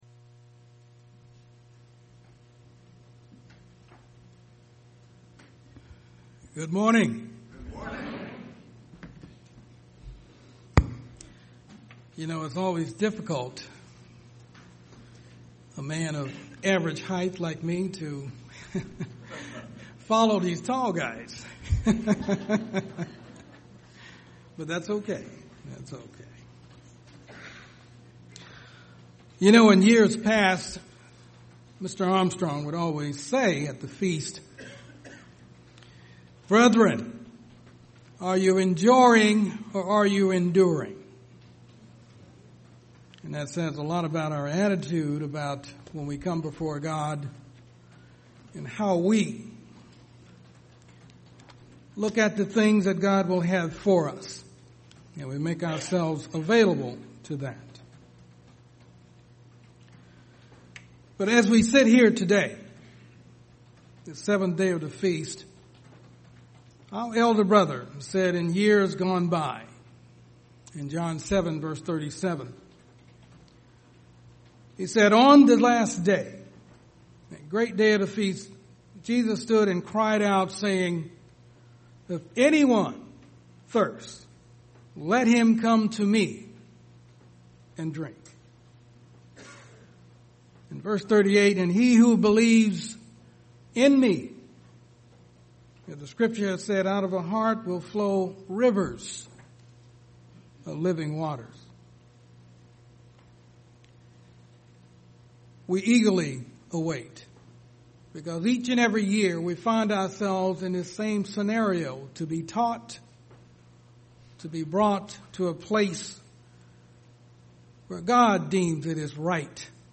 This sermon was given at the Cincinnati, Ohio 2018 Feast site.